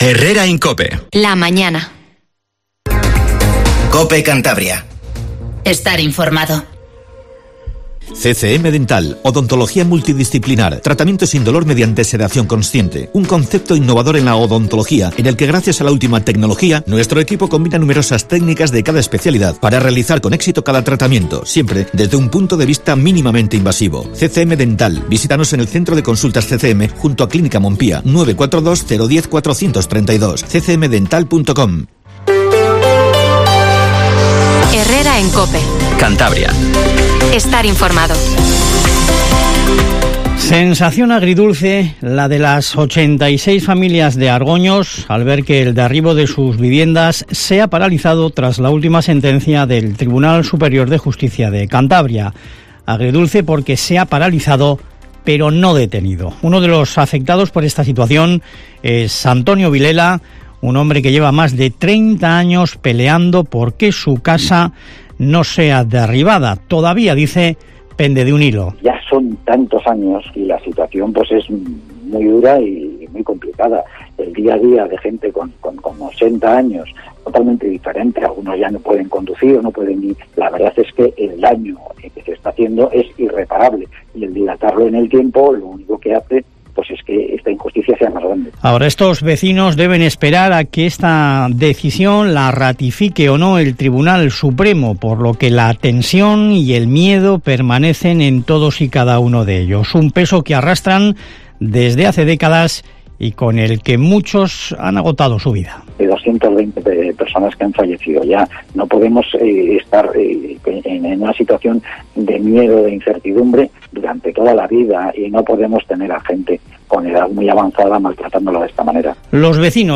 Informativo HERRERA en COPE CANTABRIA 07:50